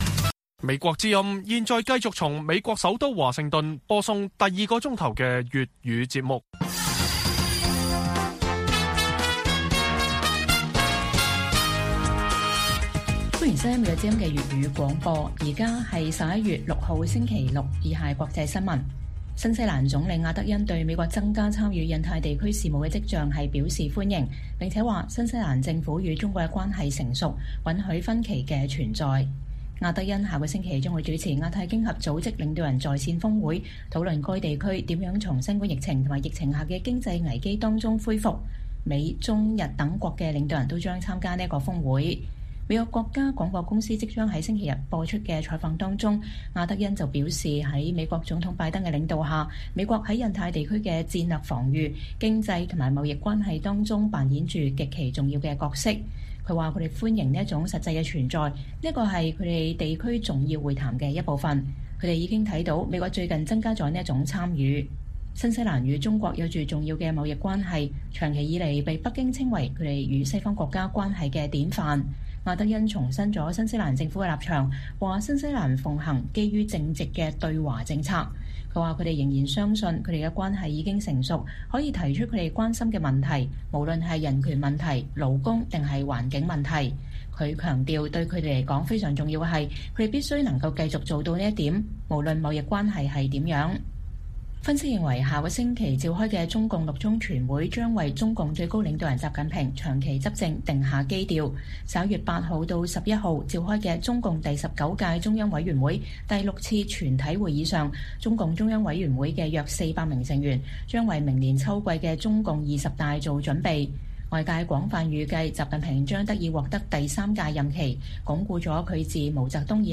粵語新聞 晚上10-11點：新西蘭與中國關係允許分歧 歡迎美國提升印太地區存在